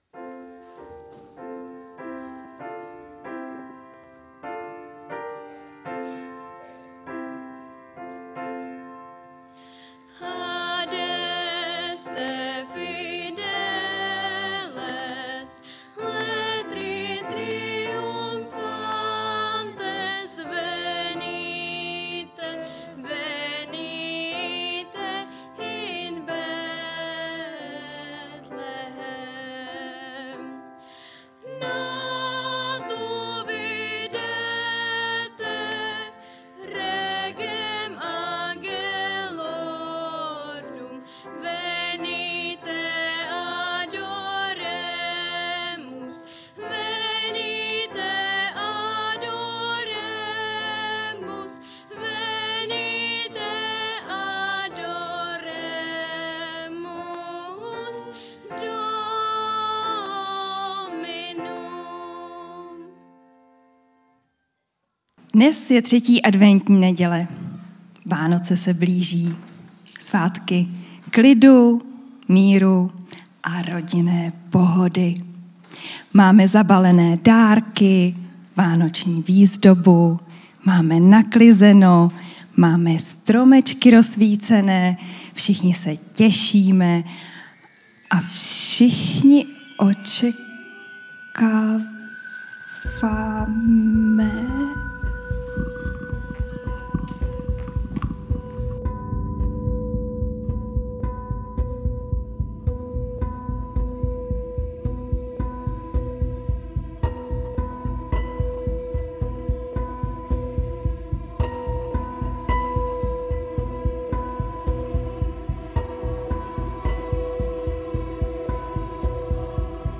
Dětská vánoční slavnost
Nedělní bohoslužby